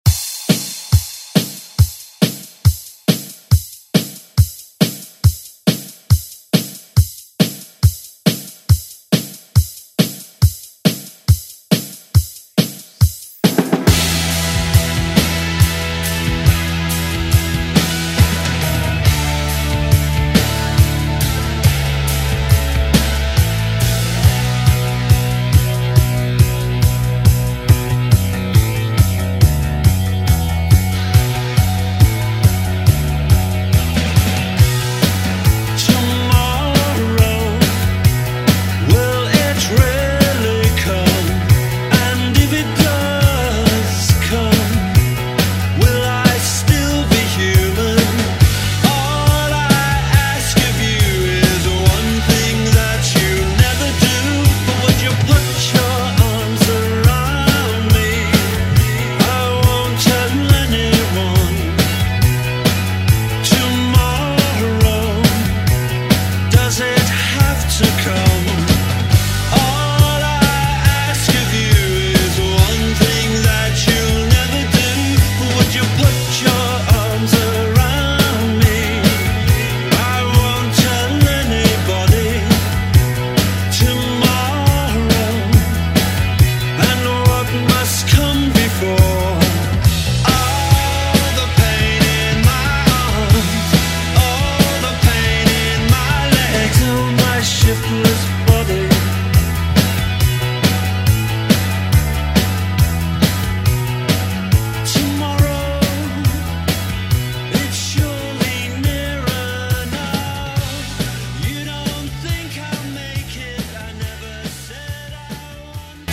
Electronic Dance Music Extended ReDrum Clean 124 bpm
Genre: EDM
Clean BPM: 124 Time